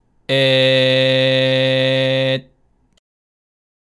(1)グー＝声帯を含めた色んなパーツを総動員して作った、強い閉じの声
音色は「ジリジリとしたような強いハリのある音」が特徴ですね！（コツは「空気を吐きすぎない」こと）
※見本のグーの声